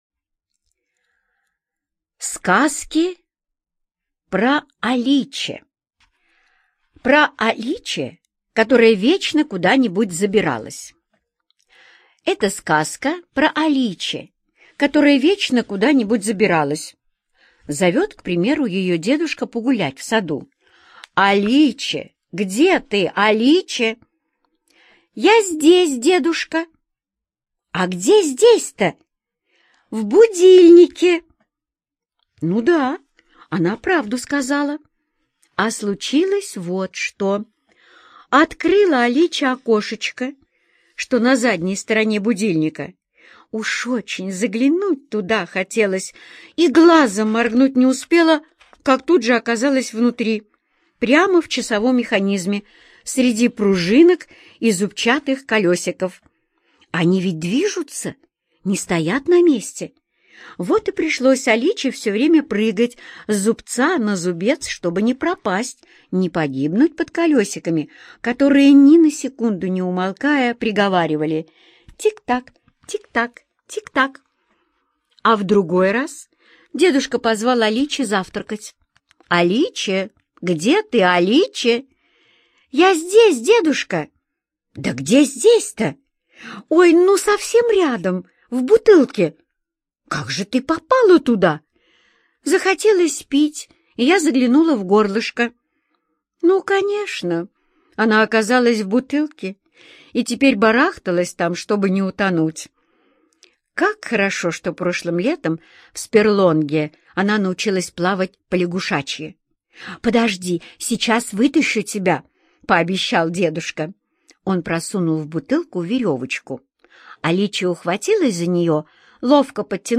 Про Аличе, которая вечно куда-нибудь забиралась - аудиосказку Родари Д. Аличе всегда куда-нибудь забиралась: то внутрь часов, то в ящик.